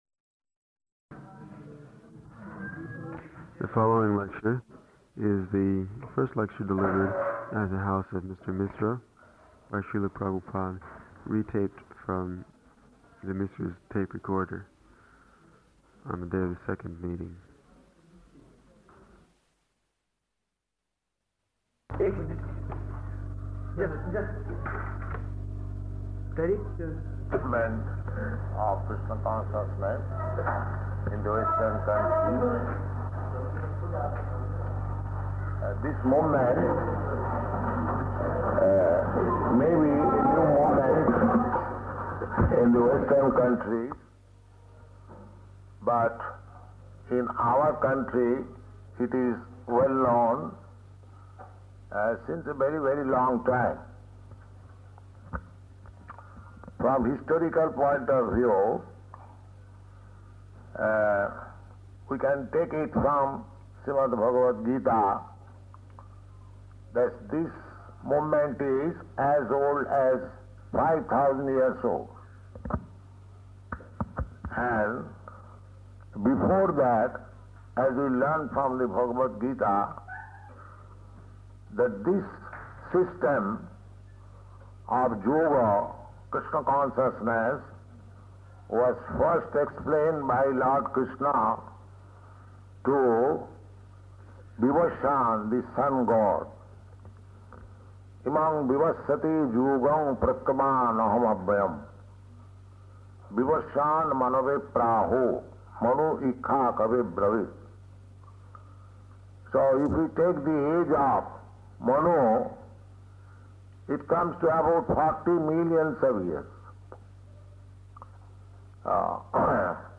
Type: Lectures and Addresses
Location: Allahabad